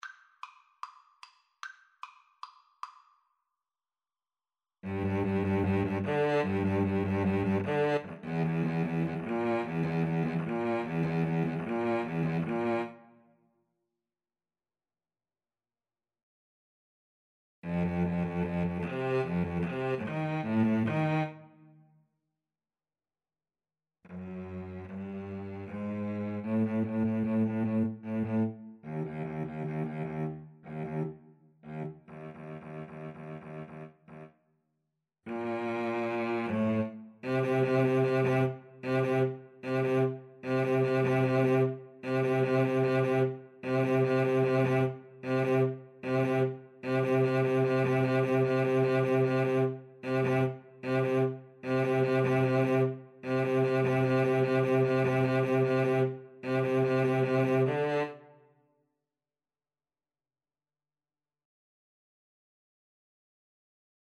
Classical
= 150 Allegro Moderato (View more music marked Allegro)
4/4 (View more 4/4 Music)